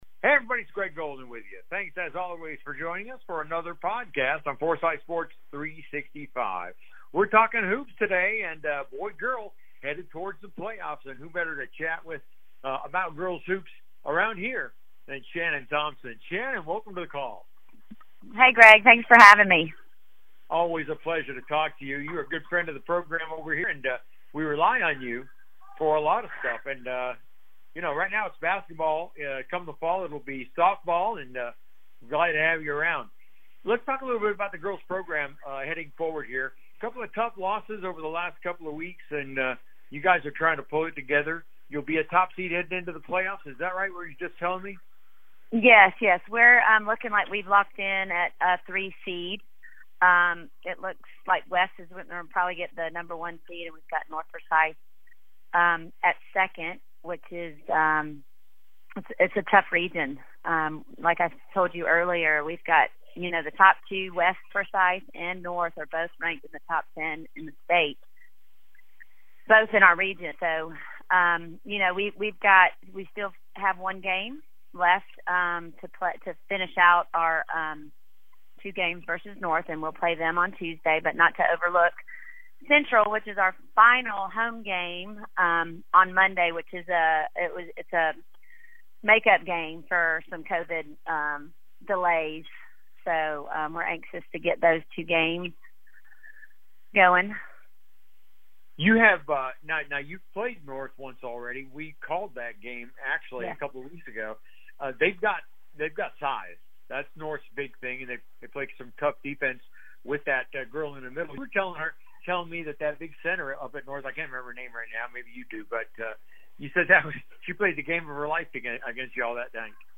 Here are our interviews